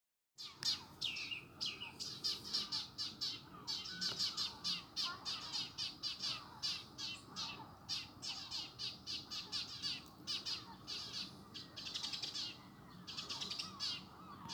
Birds -> Sparrows ->
Tree Sparrow, Passer montanus
StatusNests with young seen or heard